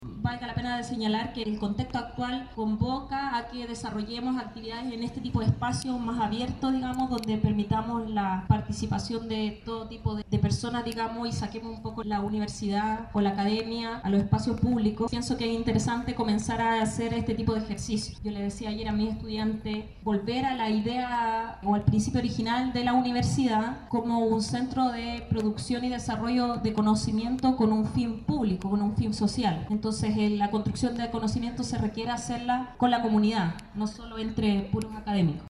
En Campus Guayacán realizan coloquio abierto a la comunidad para analizar razones de protestas en Chile
La jornada captó el interés de alumnos, funcionarios, docentes y de la comunidad, quienes se acercaron hasta la explanada verde del Campus Guayacán para escuchar las visiones de los académicos sobre la situación actual y resolver sus inquietudes sobre el origen de las protestas que se han extendido durante tres semanas en todo el territorio nacional.